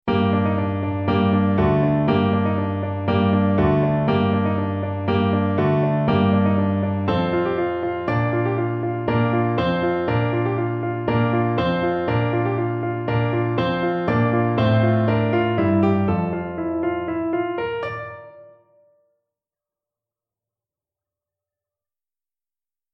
MIDIMontgeroult, Hélène De, Three Sonatas for piano, Sonata No. 1,
mvt. 1, Allegro con moto e espressione, mm.20-28